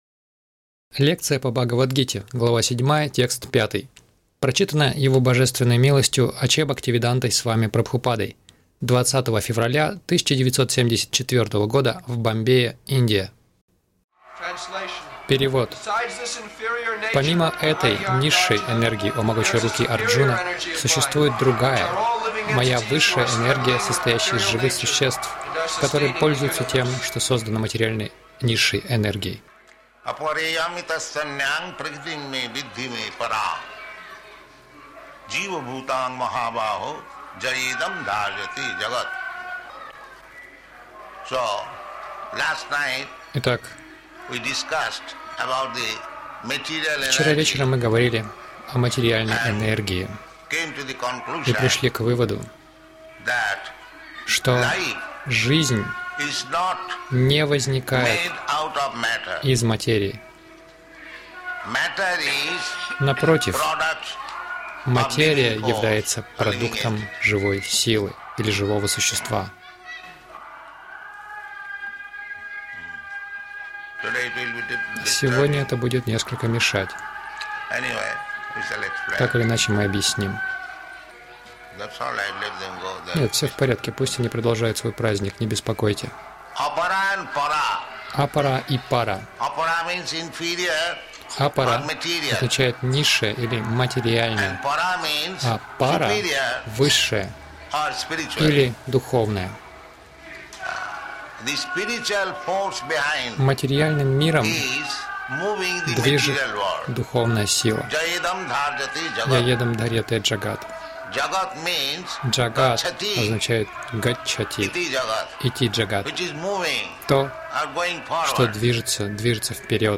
Милость Прабхупады Аудиолекции и книги 20.02.1974 Бхагавад Гита | Бомбей БГ 07.05 — Оператор материального мира — Кришна Загрузка...